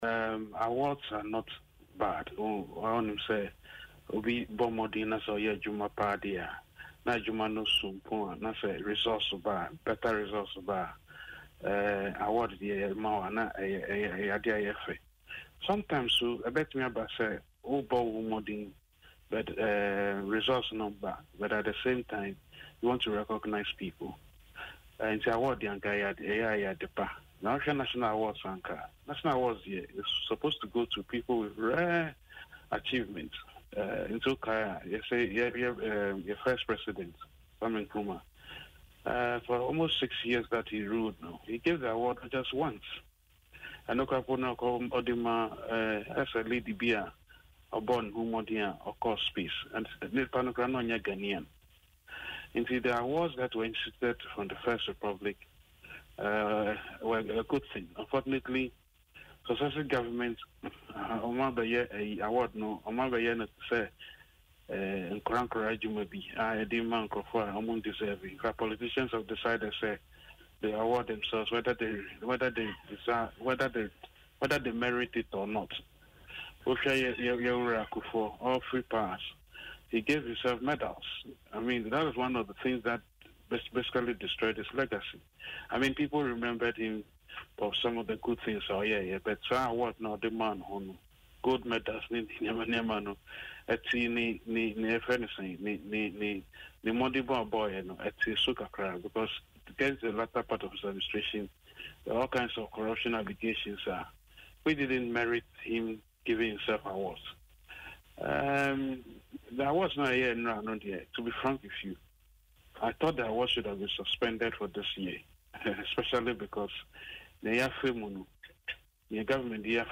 He made these remarks on Adom FM Dwaso Nsem while expressing disappointment in some of the recipients of the honourary awards.